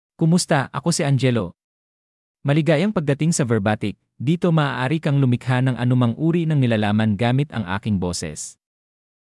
Angelo — Male Filipino (Philippines) AI Voice | TTS, Voice Cloning & Video | Verbatik AI
MaleFilipino (Philippines)
Voice sample
Male
Angelo delivers clear pronunciation with authentic Philippines Filipino intonation, making your content sound professionally produced.